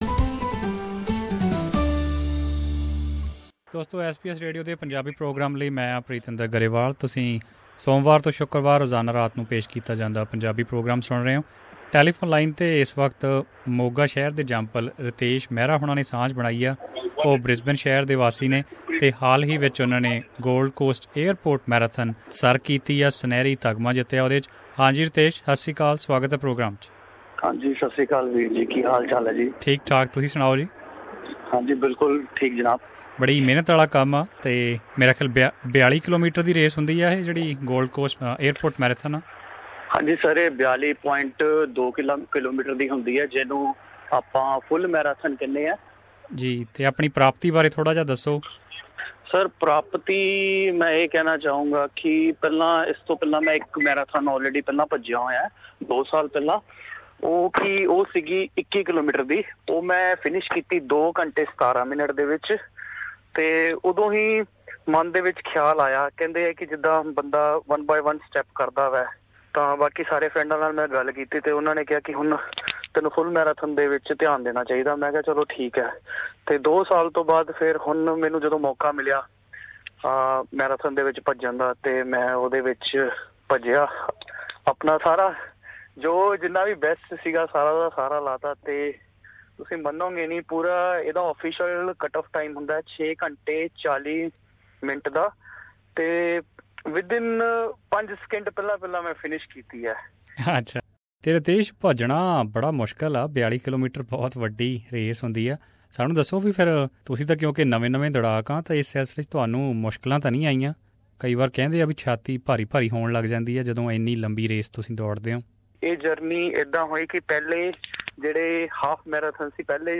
Here we have a conversation